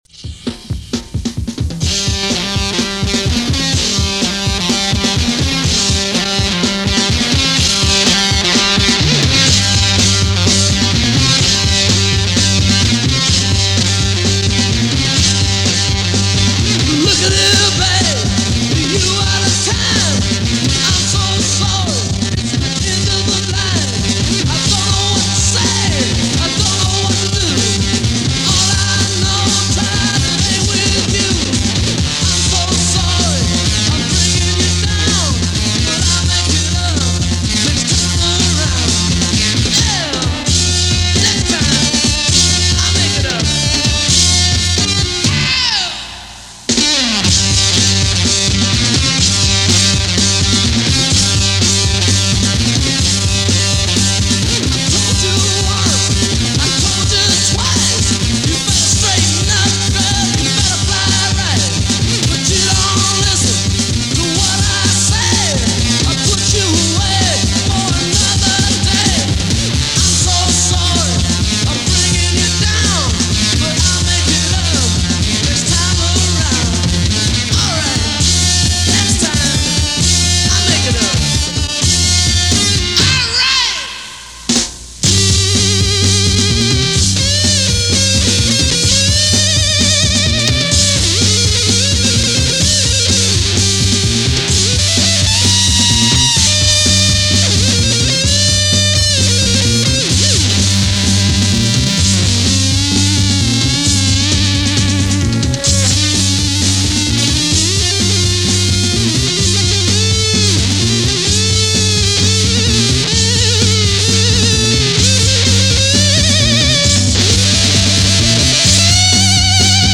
in session/demo/concert
Rightly described as “Louder Than God”, but nice guys.